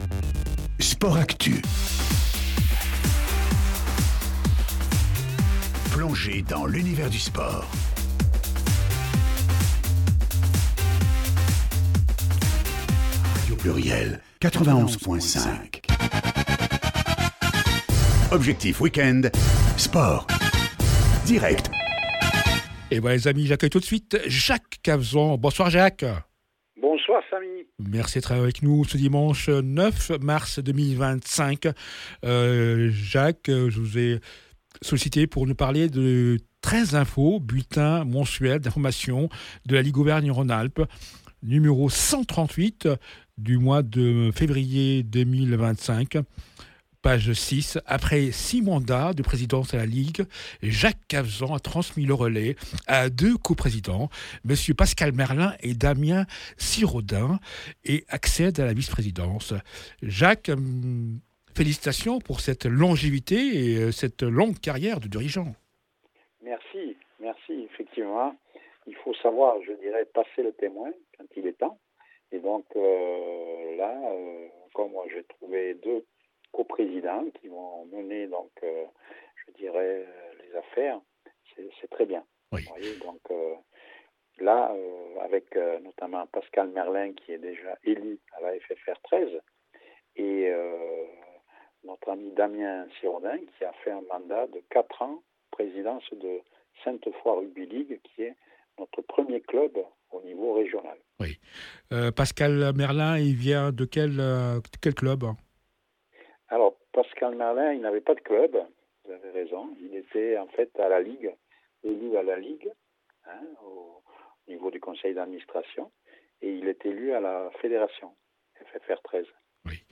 L’interview du président mois de AVRIL 2026